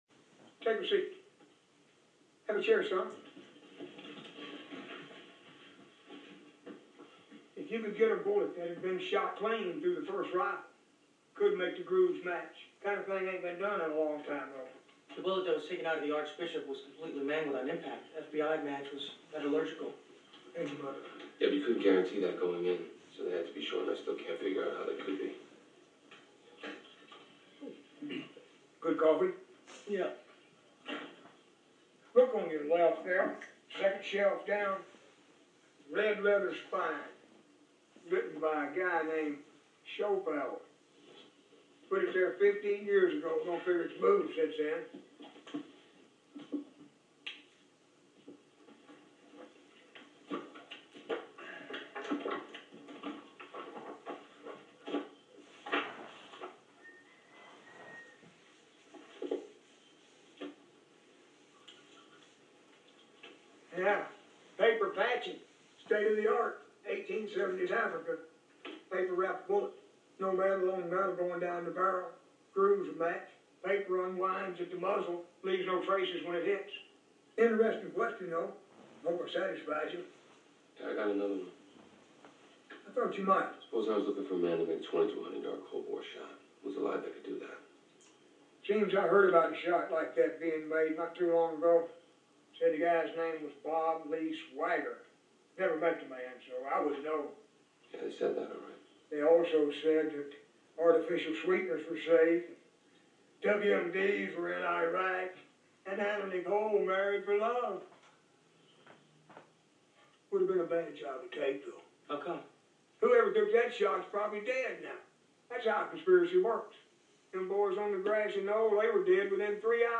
Soundclip from the movie "Shooter" starring Mark Walberg. This conversation in the movie is the best part.